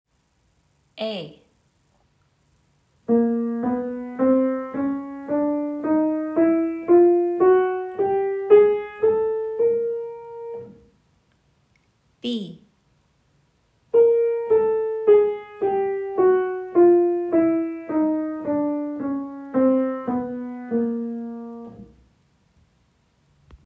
Example A shows an ascending chromatic scale beginning on B-flat.
Example B shows the descending chromatic scale beginning on B-flat.